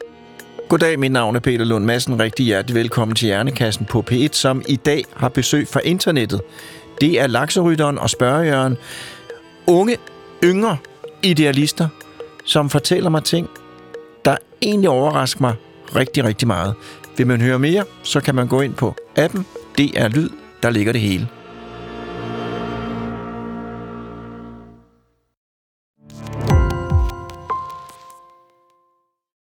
Peter Lund Madsen får hver uge besøg af eksperter i studiet. Alle emner kan blive belyst - lige fra menneskekroppens tarmflora til ekspeditioner på fremmede planeter.